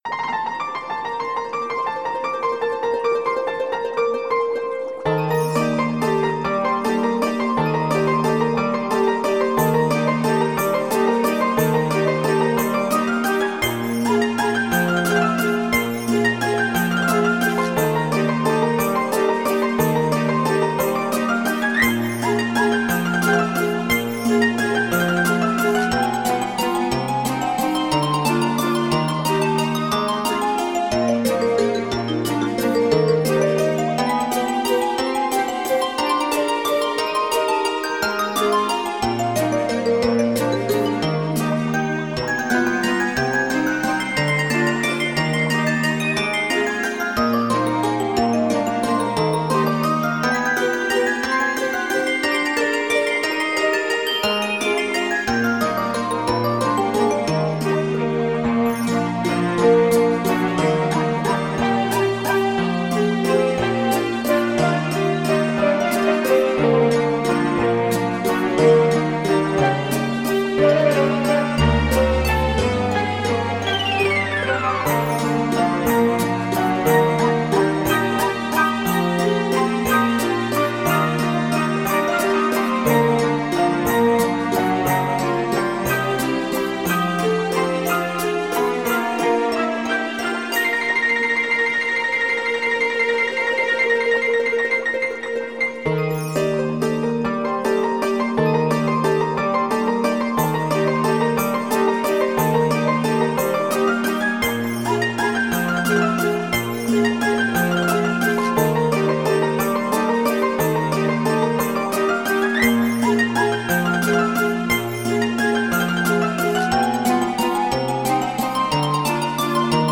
game BGM-like classical/folk and original music in mp3